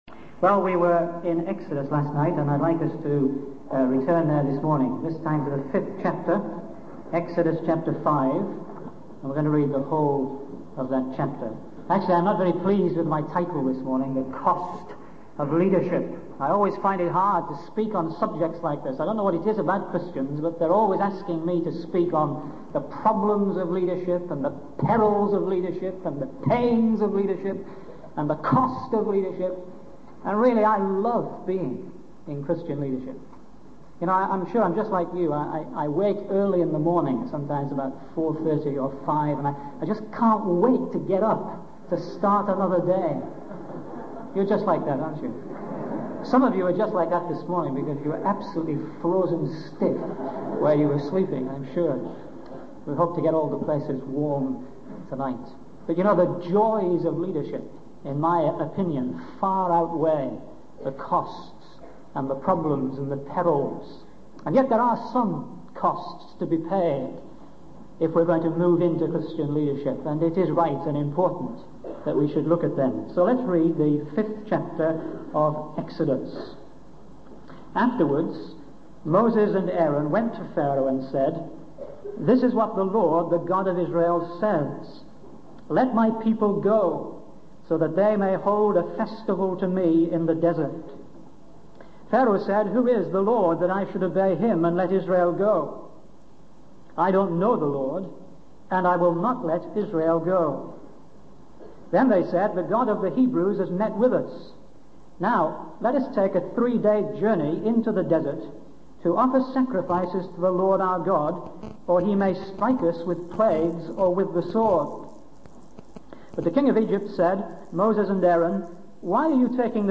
In this sermon, the speaker discusses the challenges and hardships faced by Christian leaders, using the examples of Moses and Paul. He emphasizes that occasional exhaustion and feelings of not being able to continue are not abnormal for leaders in the front line of the battle.